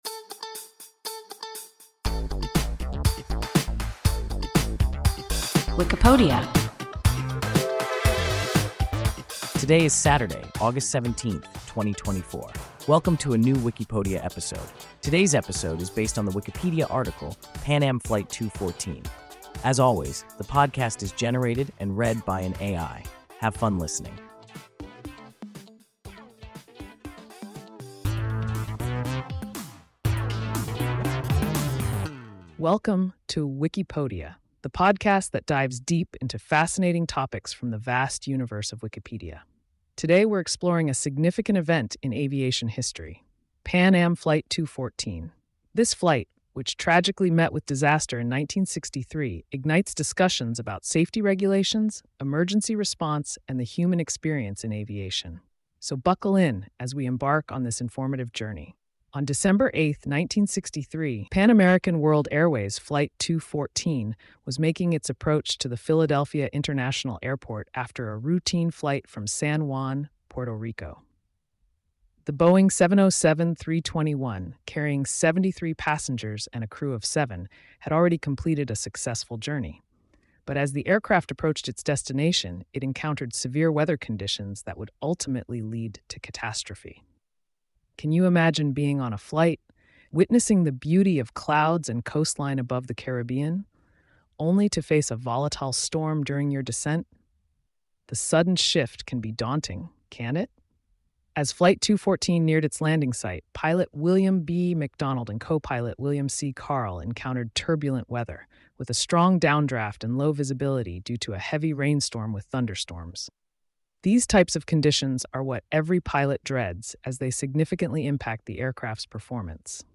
Pan Am Flight 214 – WIKIPODIA – ein KI Podcast